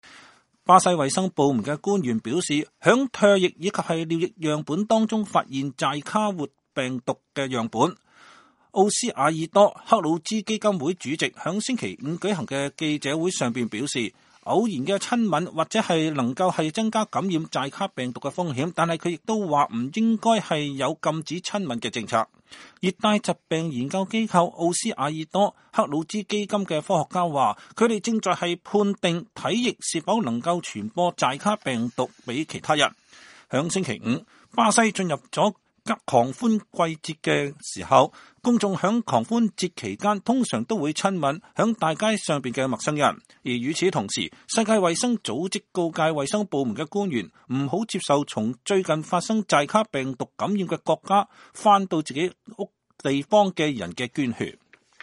2016-02-06 美國之音視頻新聞: 巴西說在唾液和尿液中發現寨卡活病毒